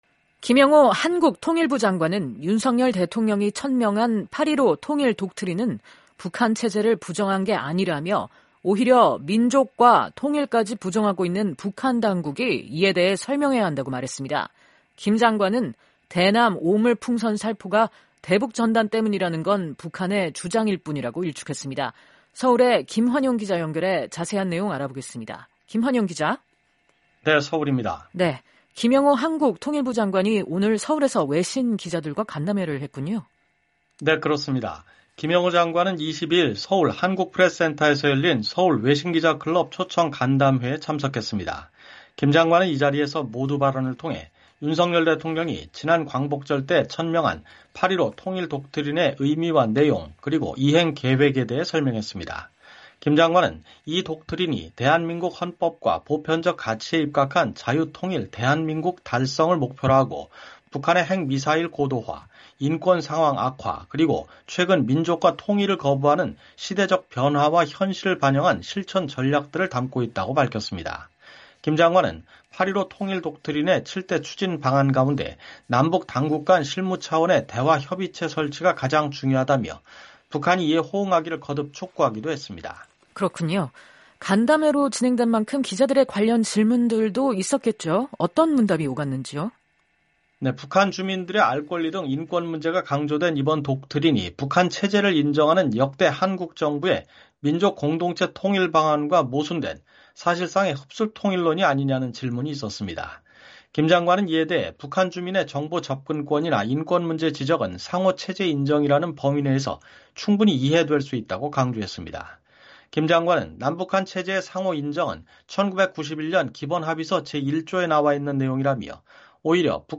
기자를 연결해 자세한 내용 알아보겠습니다.